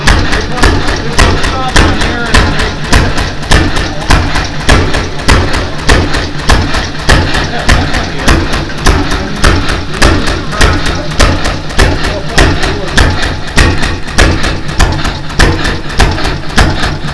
Below is a sound file of the 8 HP Evans running, with some small video clips of both the Evans and the Ingersoll Sergeant running...